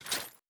Throw9.wav